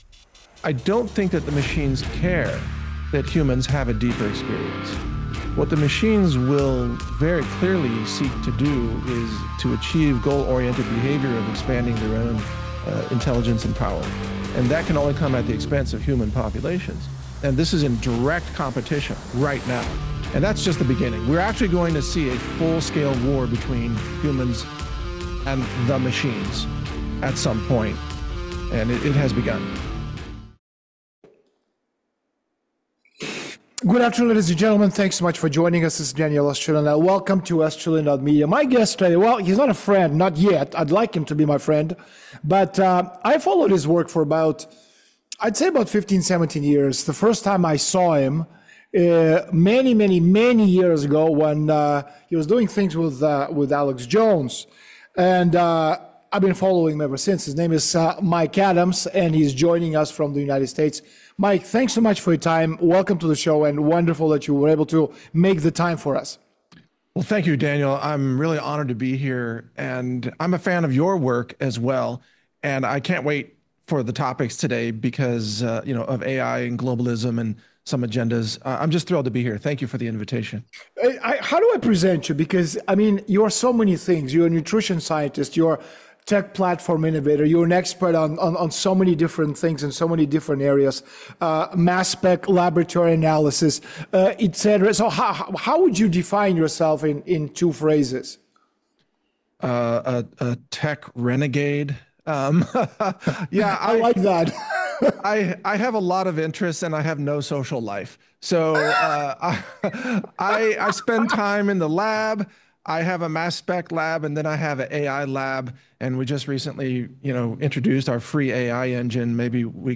Daniel Estulin with Mike Adams: AI, Globalism, and the Battle for Humanity - Natural News Radio